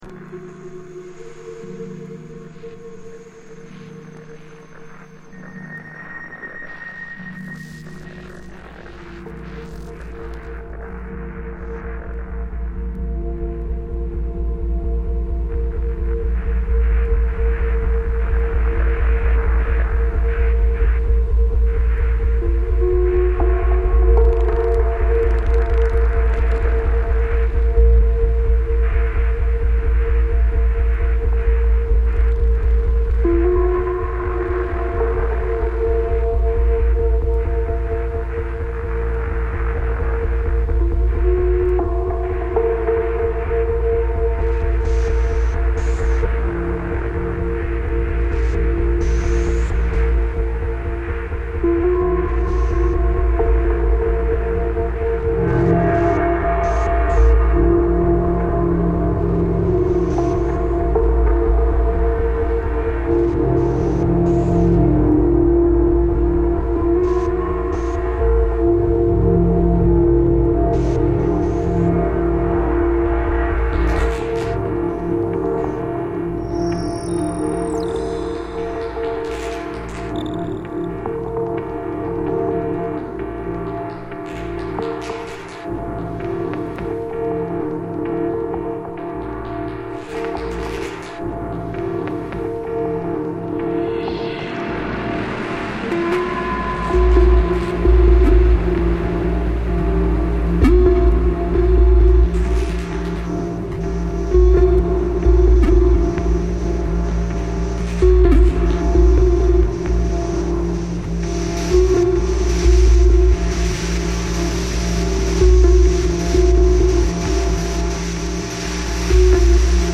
electro-acoustic music